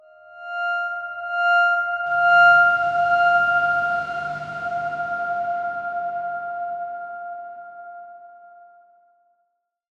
X_Darkswarm-F5-pp.wav